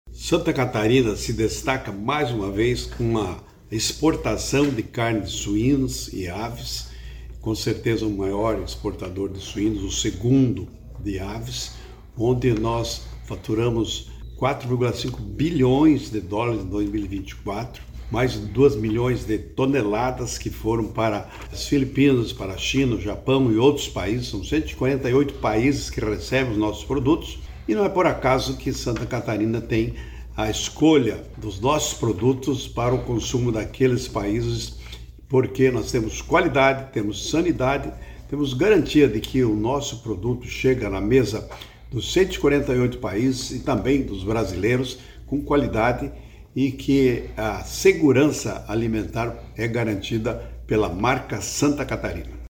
SECOM-Sonora-secretario-da-Agricultura-5.mp3